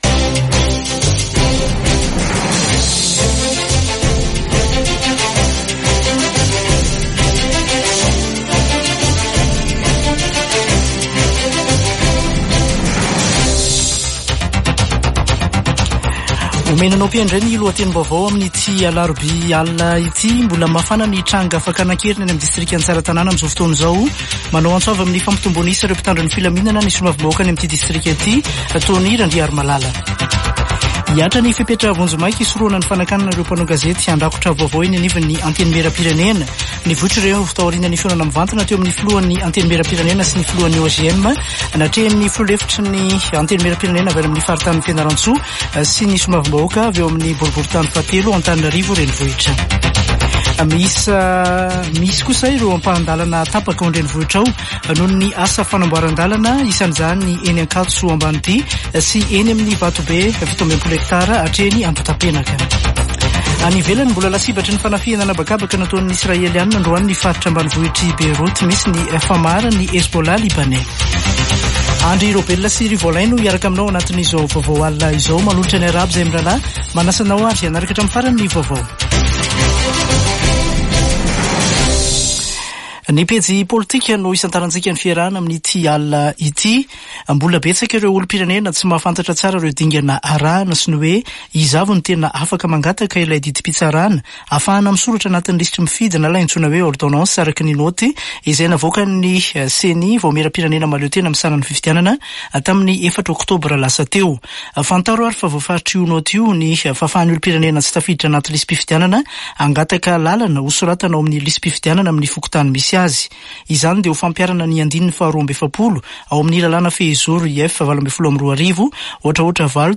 [Vaovao hariva] Alarobia 16 ôktôbra 2024